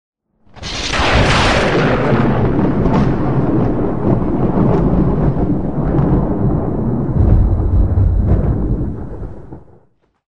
Lightening Sound Effect Free Download
Lightening